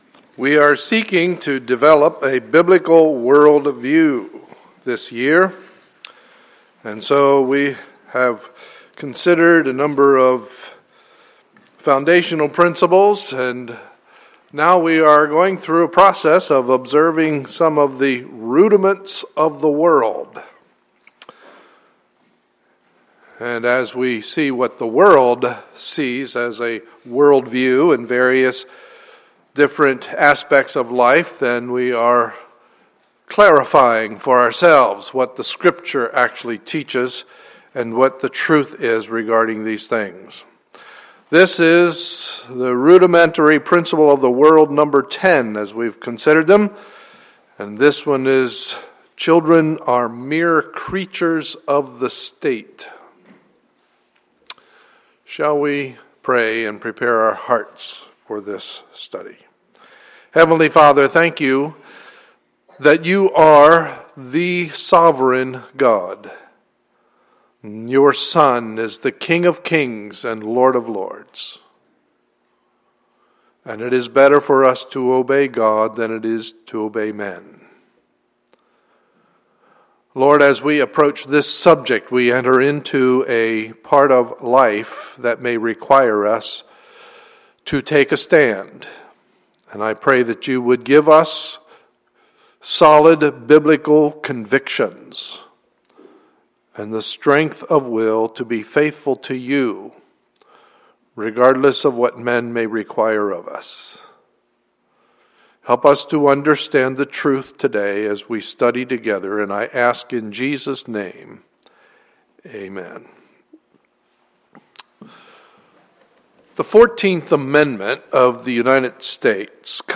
Sunday, May 18, 2014 – Morning Service